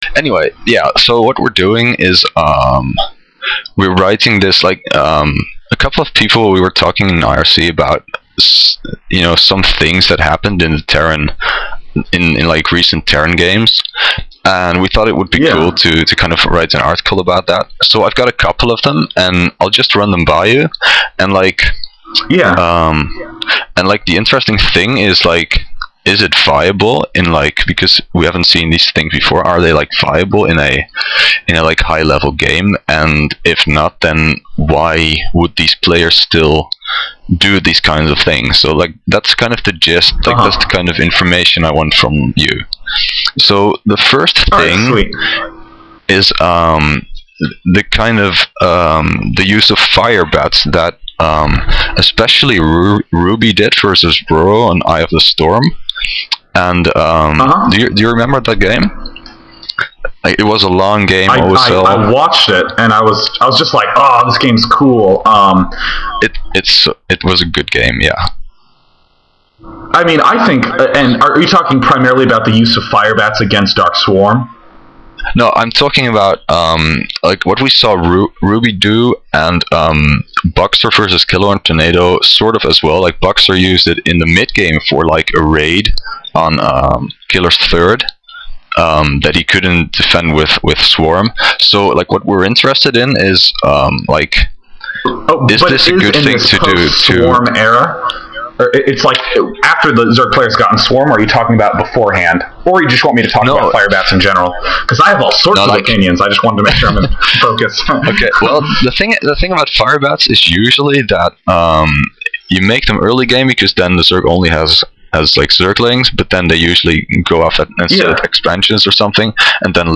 Games mentioned in Day9 interview
DayInterview.mp3